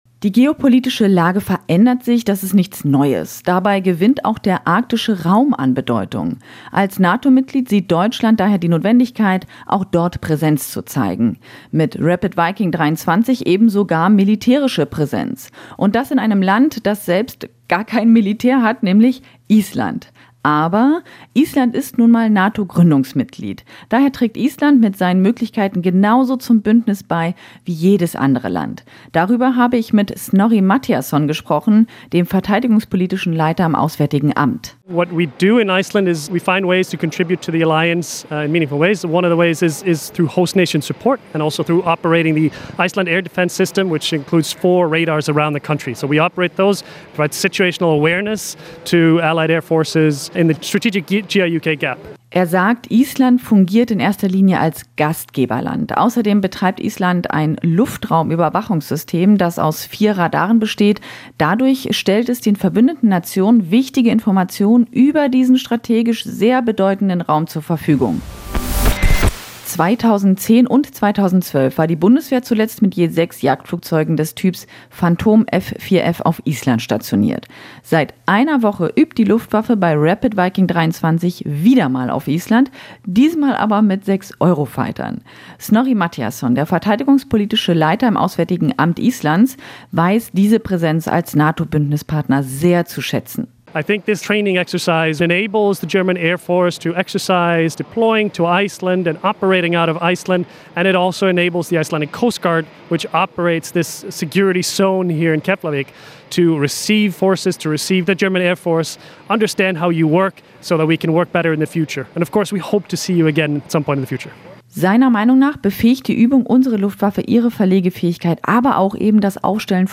Soldat mit Mikrofon interviewt Verteidigungsminister Islands